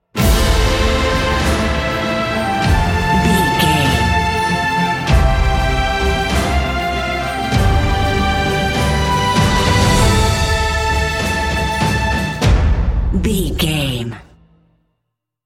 Epic / Action
Uplifting
Aeolian/Minor
brass
cello
double bass
drums
horns
strings
synthesizers
violin